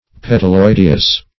Search Result for " petaloideous" : The Collaborative International Dictionary of English v.0.48: Petaloideous \Pet`al*oid"e*ous\, a. (Bot.) Having the whole or part of the perianth petaline.